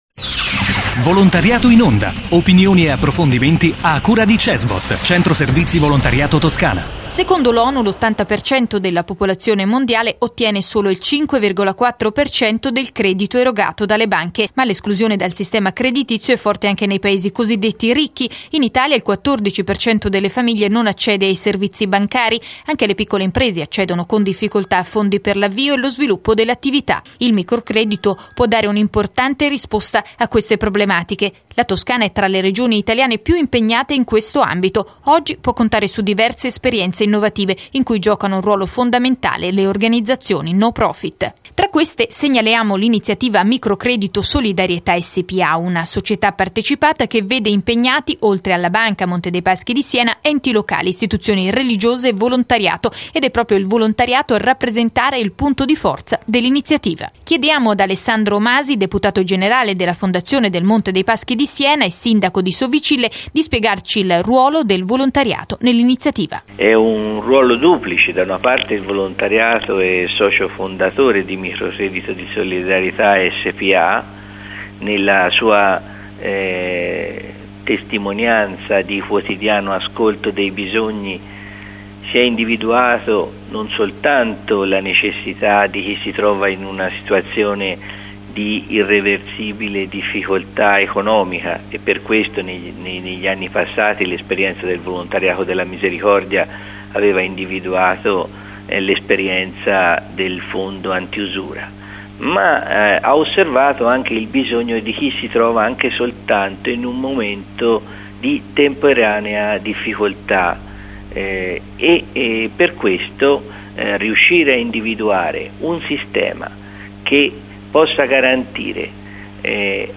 Intervista a Alessandro Masi - deputato generale della Fondazione del Monte dei Paschi di Siena e sindaco di Sovicille